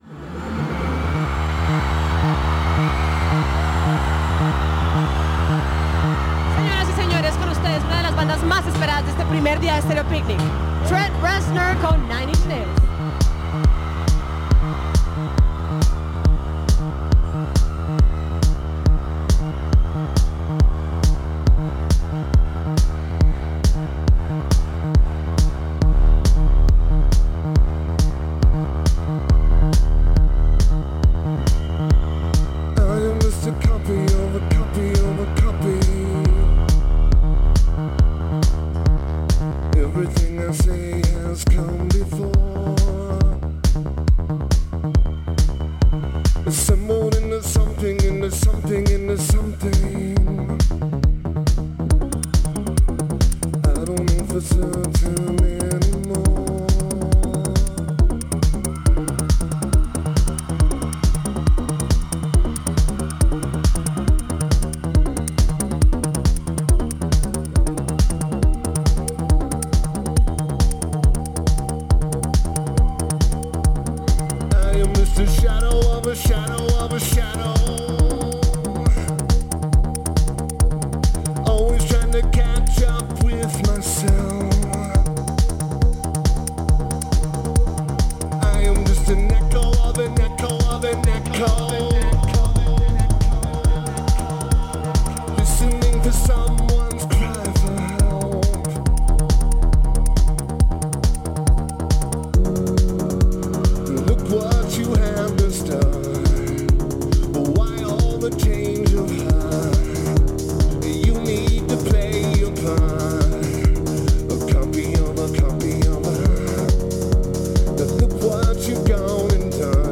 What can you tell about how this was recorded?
Taper: Soundboard